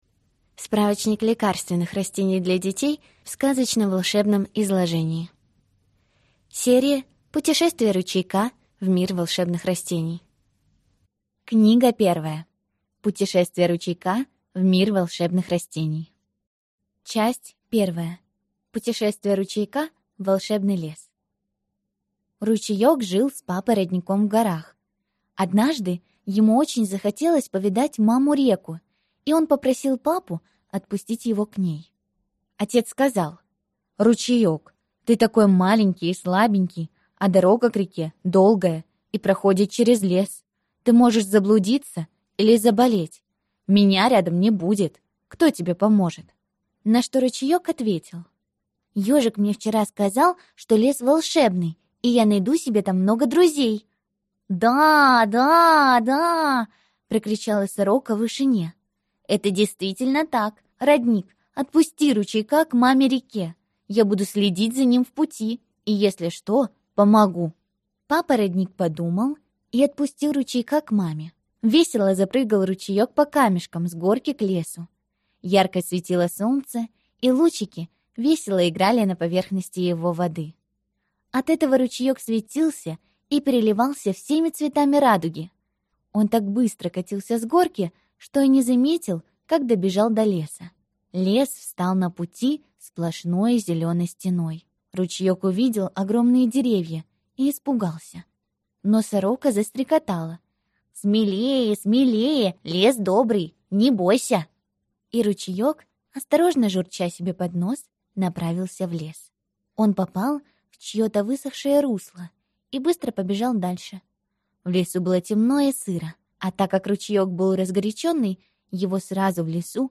Аудиокнига Путешествие Ручейка в мир волшебных растений. Книга 1 | Библиотека аудиокниг